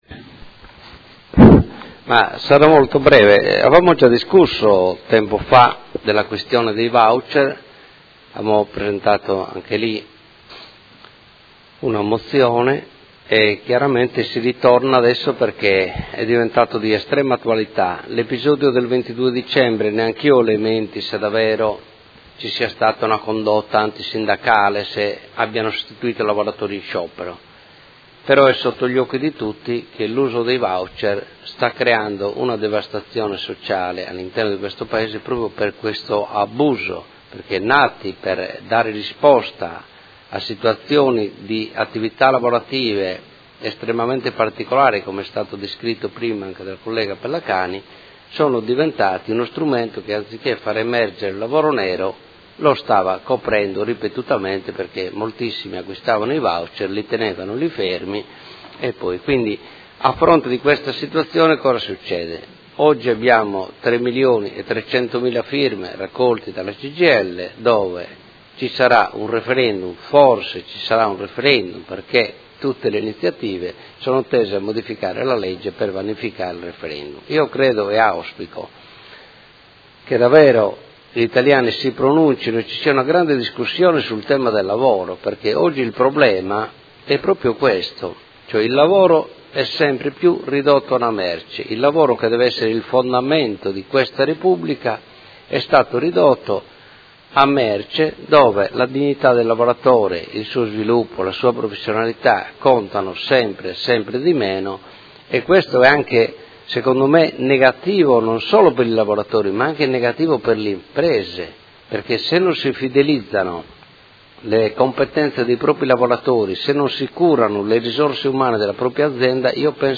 Marco Cugusi — Sito Audio Consiglio Comunale
Interrogazione del Consigliere Rocco (FAS-SI), dal Consigliere Cugusi (SEL) e dai Consiglieri Campana e Chincarini (Per Me Modena) avente per oggetto: Utilizzo dei Voucher per pagare lavoratori in sostituzione di altri lavoratori in sciopero. Dibattito